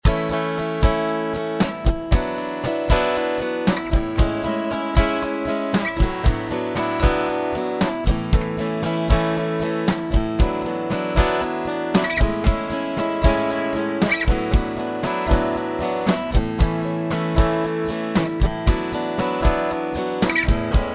Instrumental mp3 Track